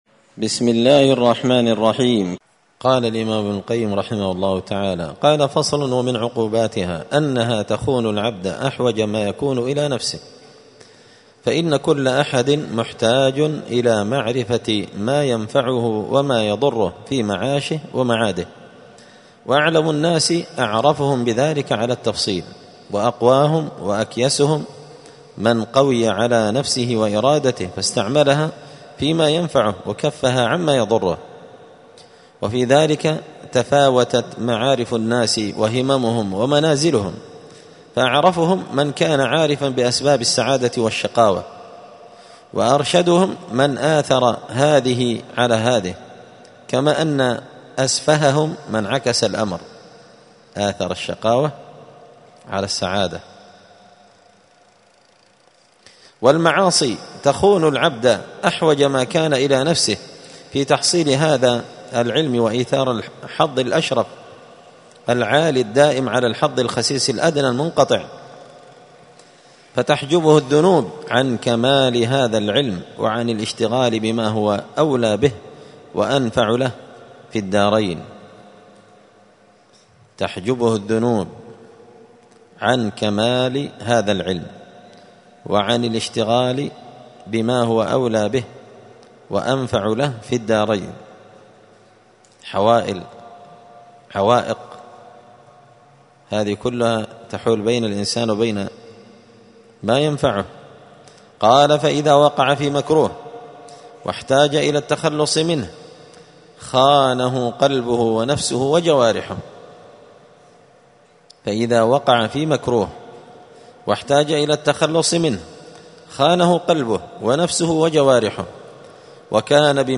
دار الحديث السلفية بمسجد الفرقان بقشن المهرة اليمن 📌الدروس الأسبوعية